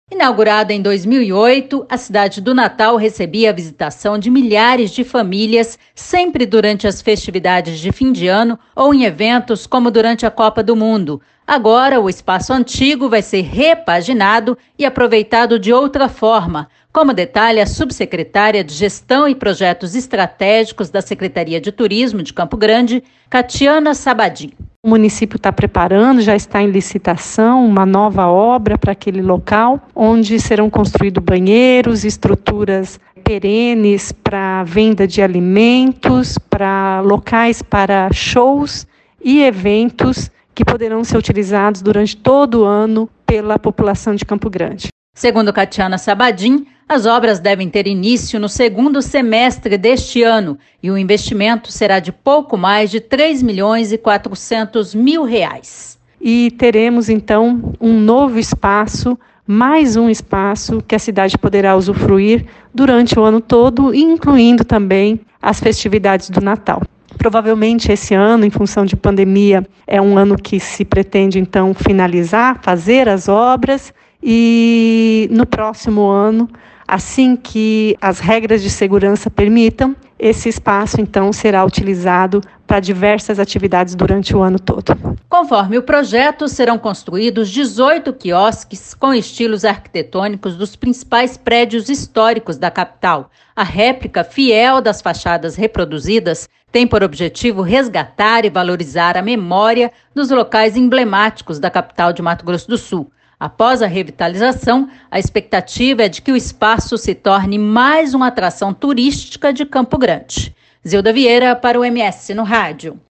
Ela disse no programa MS no Rádio da Educativa FM 104,7, que as obras devem ter início no segundo semestre deste ano e o investimento será de pouco mais de R$ 3 milhões e 400 mil.